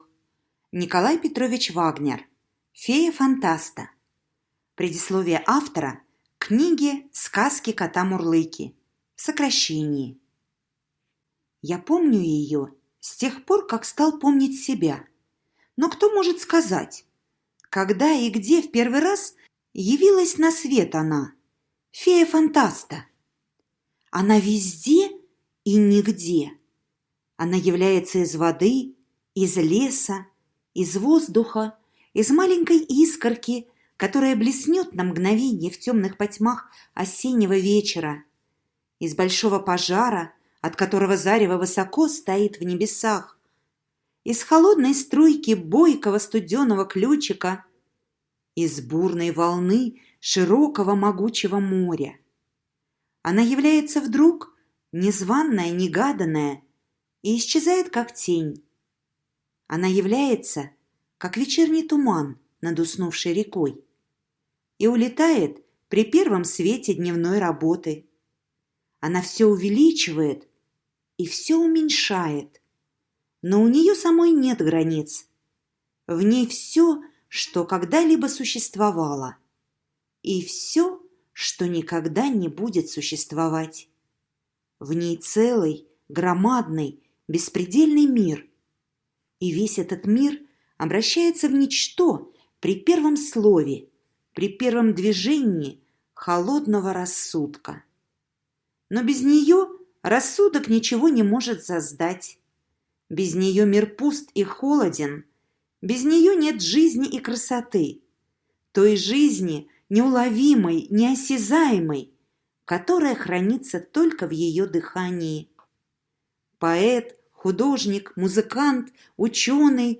Фея Фантаста - аудиосказка Вагнера - слушать онлайн